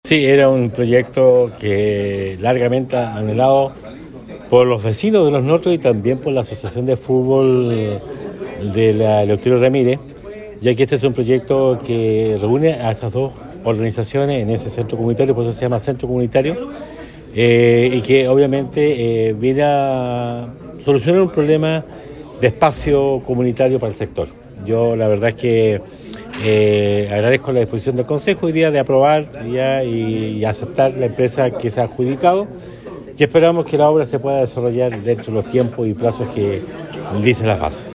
El Centro Comunitario de Los Notros se perfila como un espacio multifuncional que brindará numerosas oportunidades a los residentes de la localidad. Entre las características destacadas se encuentran salas para actividades recreativas, talleres educativos, áreas de reunión comunitaria además se incluirán instalaciones deportivas para fomentar el ejercicio y la vida activa entre los habitantes, como lo señaló el alcalde de Osorno Emeterio Carrillo.